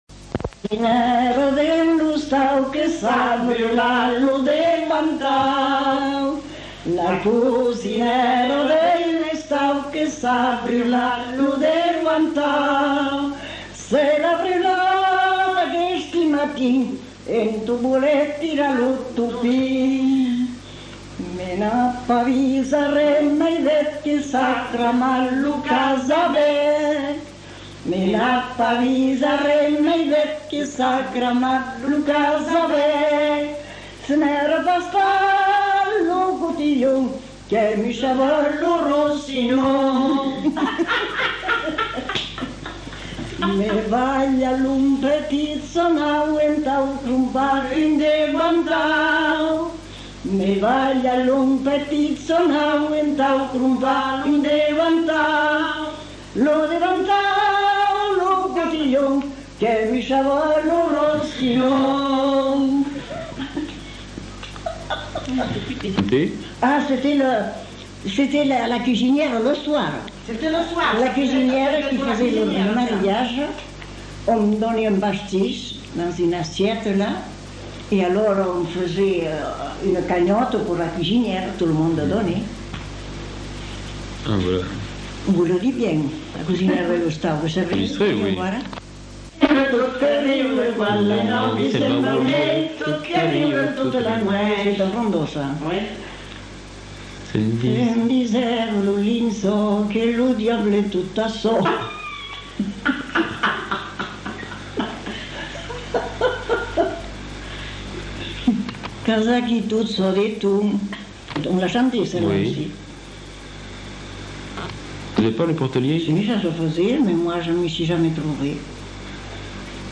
Lieu : Mont-de-Marsan
Genre : chant
Effectif : 2
Type de voix : voix de femme
Production du son : chanté
Notes consultables : Bribes de chant en fin de séquence puis évocation de la coutume du "pòrta-lièit".